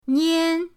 nian1.mp3